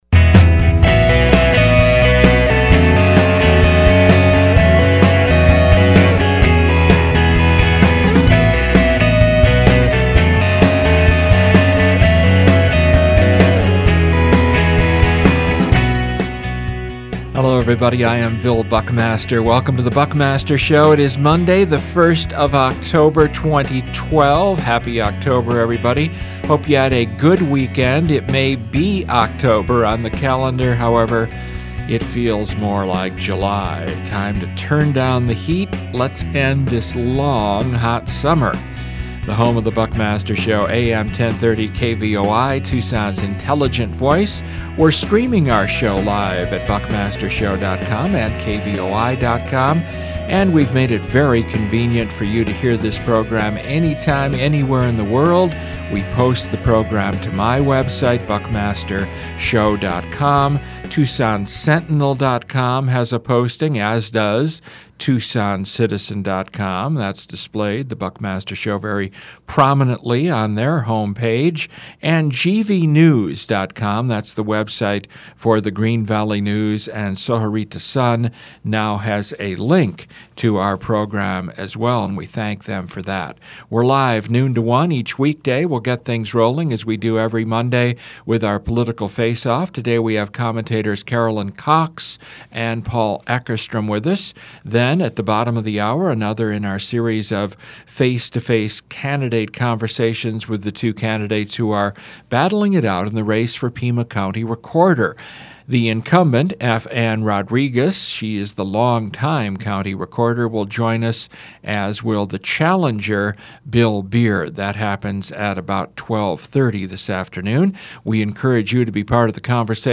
Pima County Recorder debate